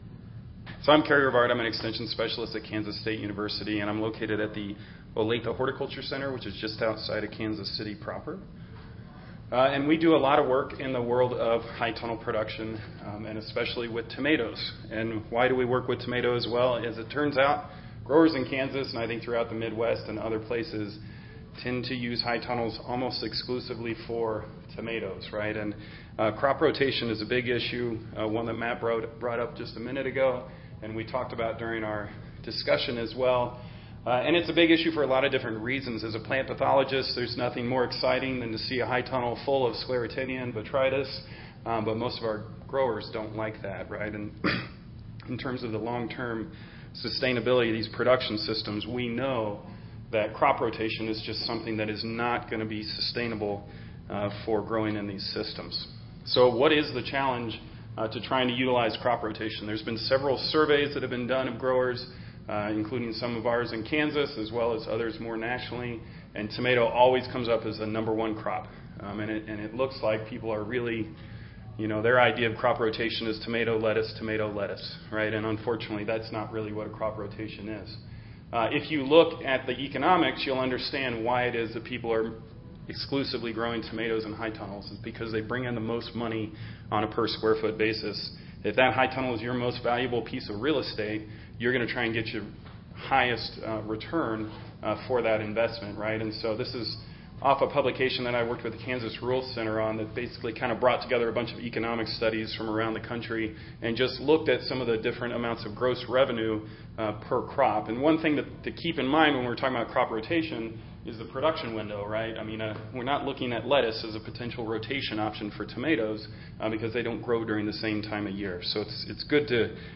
2019 ASHS Annual Conference
Audio File Recorded Presentation